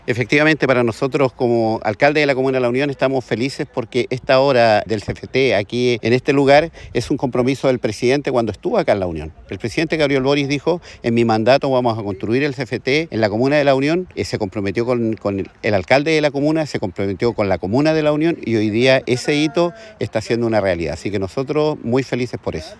Alcalde-de-La-Union-Andres-Reinoso.mp3